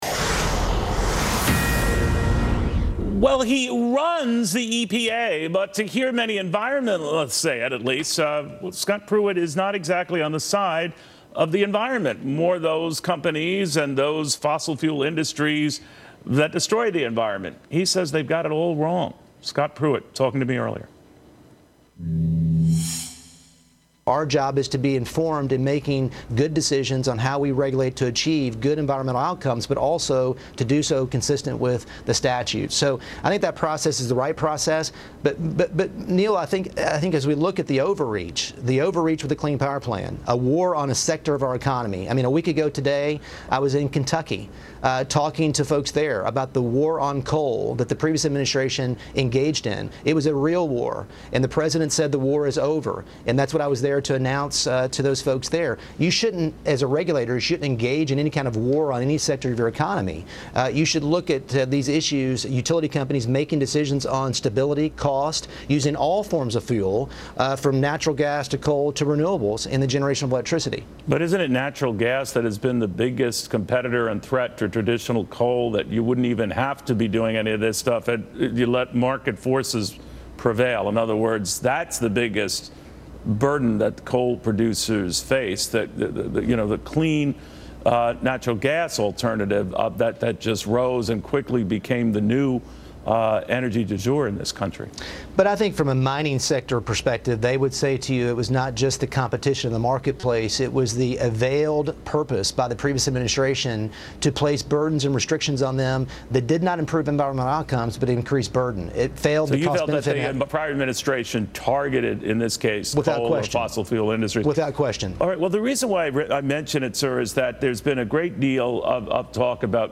Interviewed Tuesday on Fox News with Neil Cavuto, Pruitt cited the Obama administration’s ‘war on coal’ as overreach by the government, something he has long argued even in the days when he was the attorney general of Oklahoma.